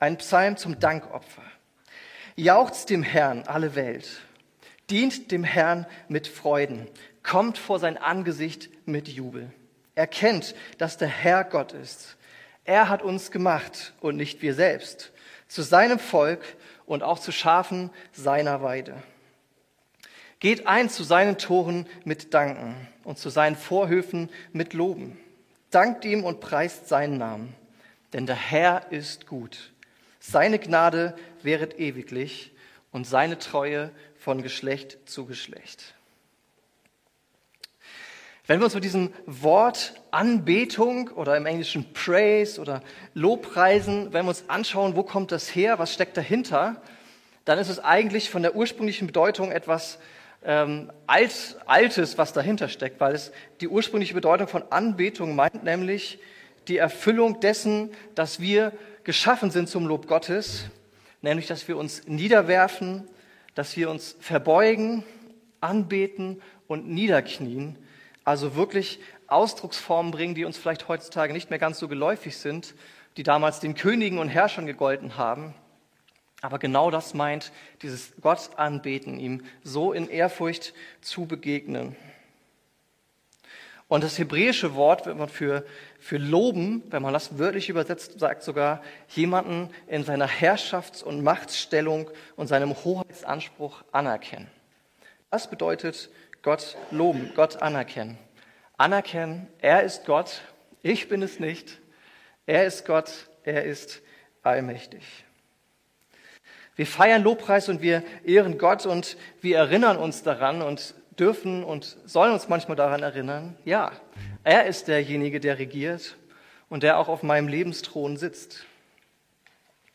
Es fehlen die ersten 2 Minuten der Predigt aufgrund technischer Probleme